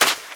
STEPS Sand, Run 20.wav